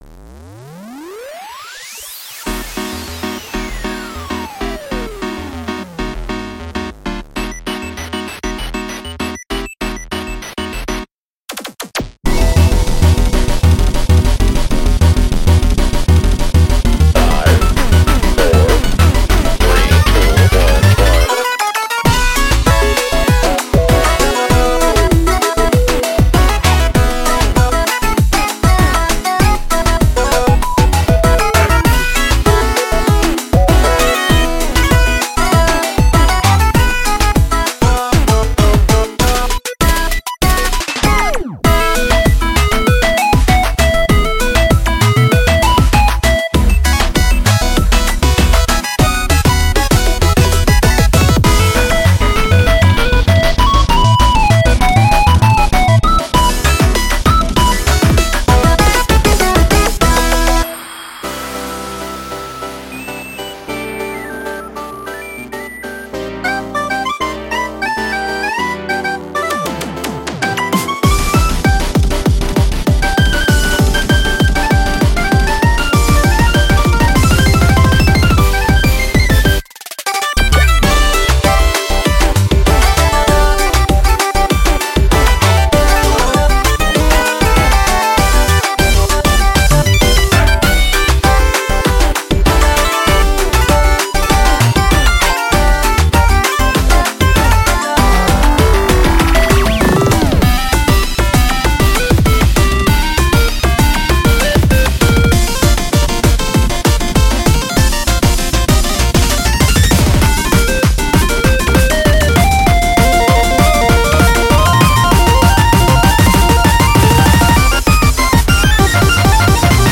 BPM196
Audio QualityMusic Cut